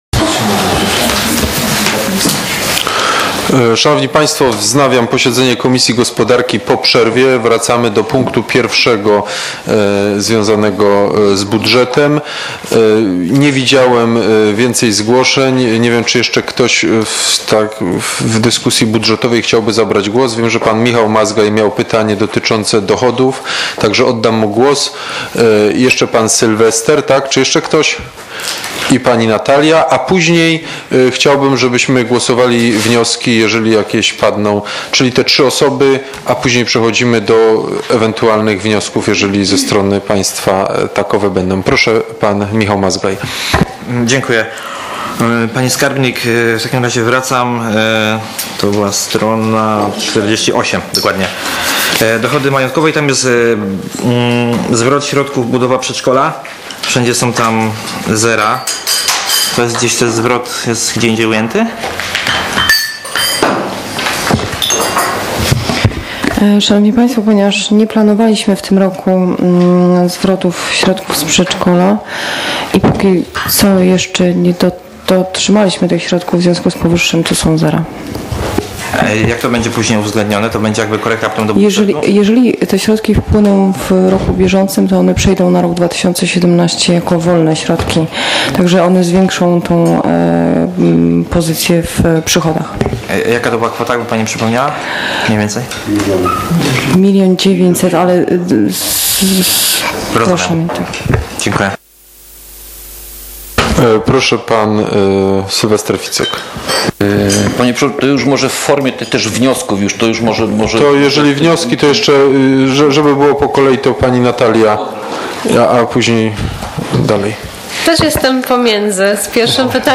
z posiedzenia Komisji Gospodarki Miejskiej w dniu 13.12.2016 r.